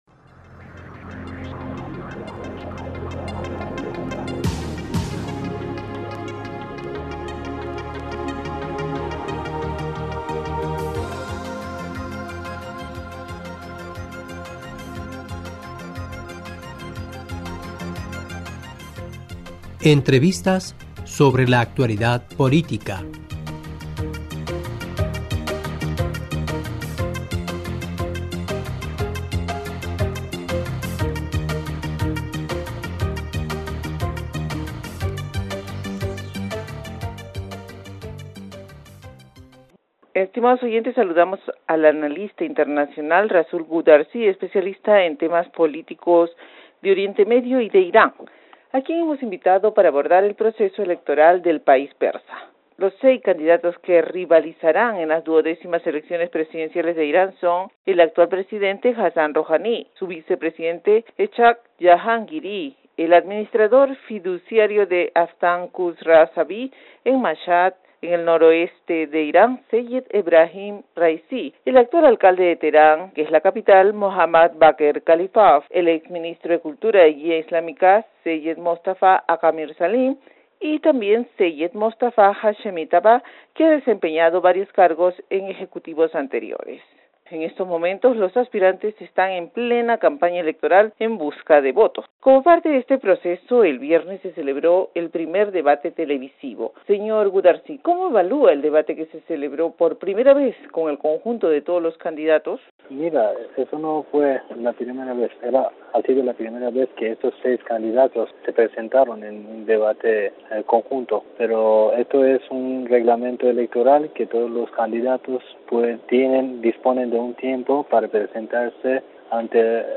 Estimados oyentes saludamos al analista